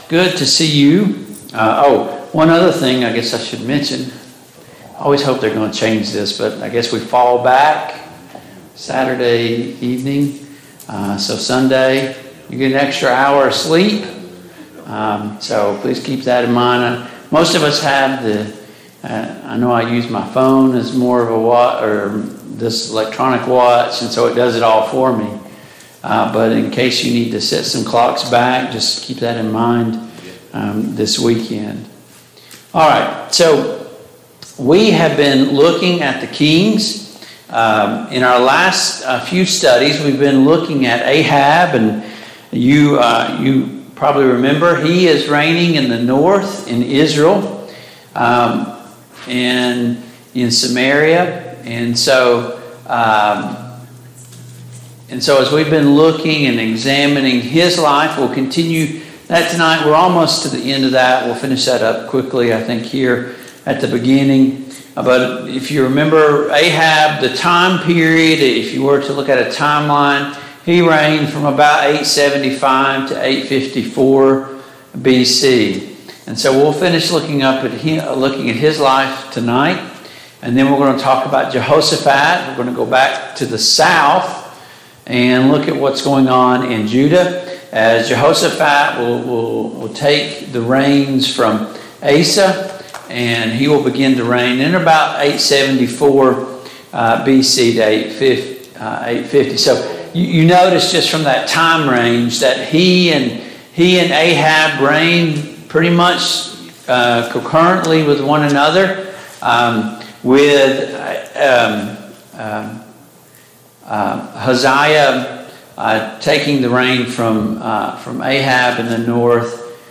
The Kings of Israel Passage: 1 Kings 22, 2 Kings 9 Service Type: Mid-Week Bible Study Download Files Notes « 2.